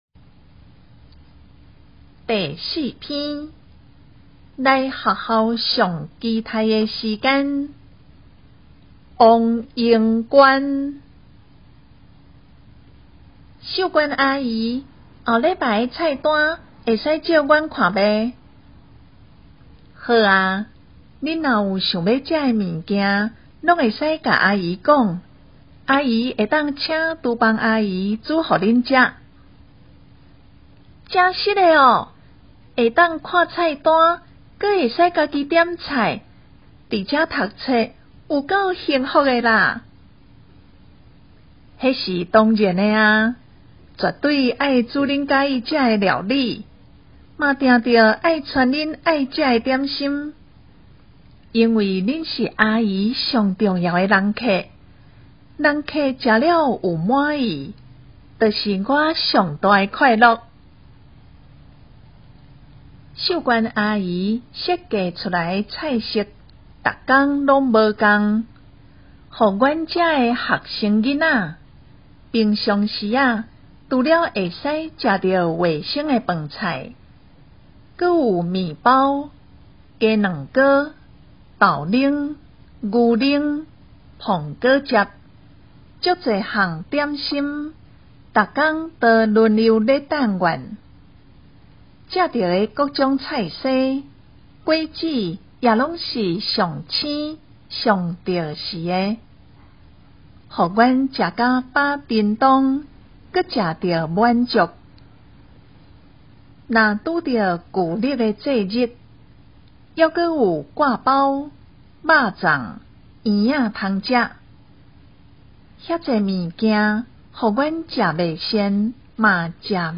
閩南語朗讀-04_來學校上期待的時間.mp3